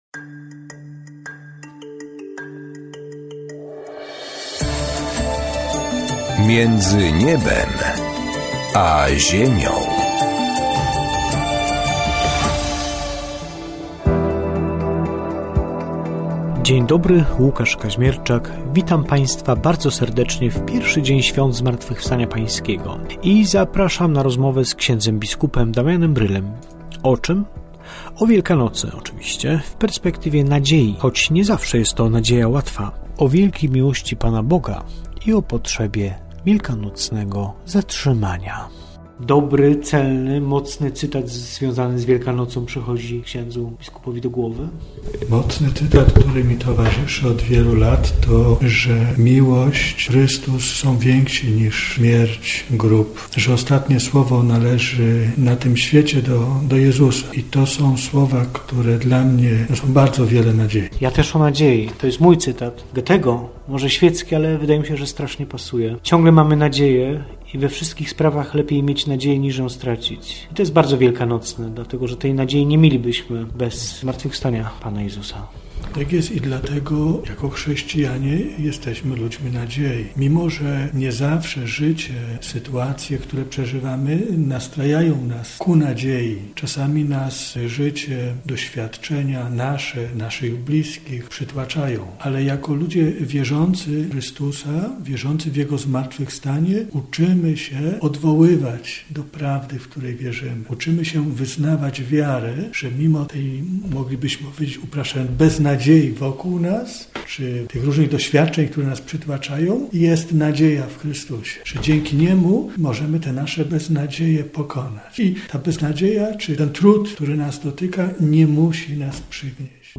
Rozmowa z biskupem Damianem Brylem o Wielkanocy w perspektywie nadziei oraz o Bożym Miłosierdziu.